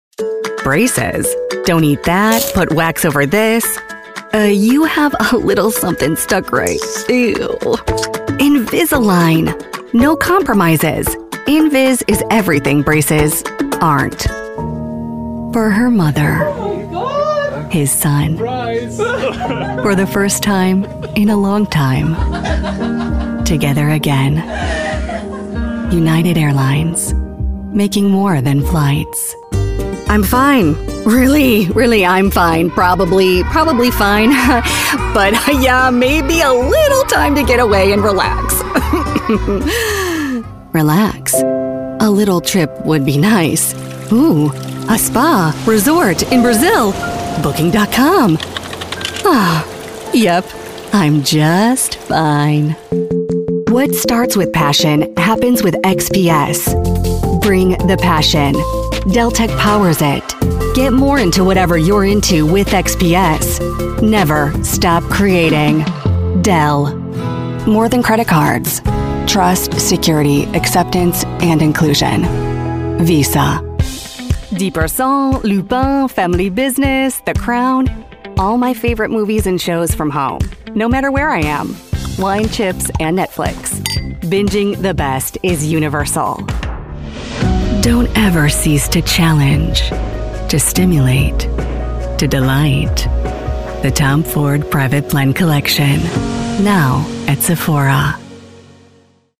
Suchen Sie eine professionelle nordamerikanische Sprecherin?
Artikulieren
Natürlich